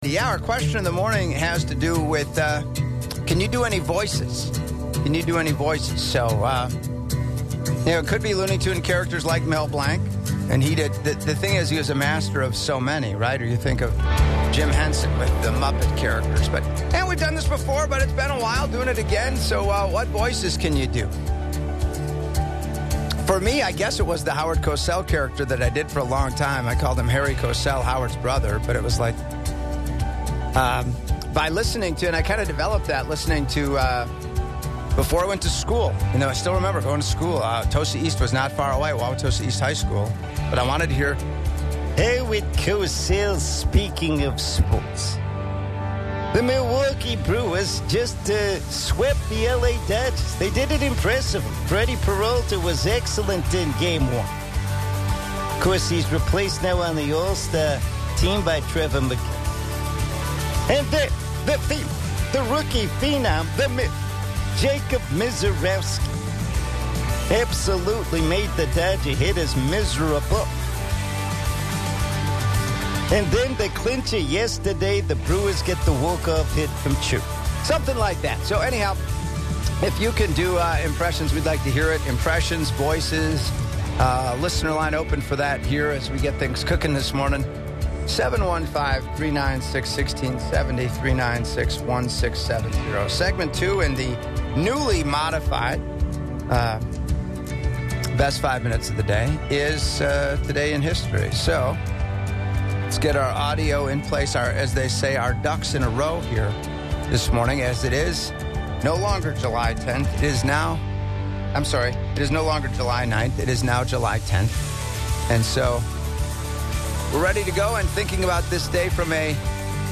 Voices/Impressions